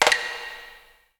A#3 STICK0DL.wav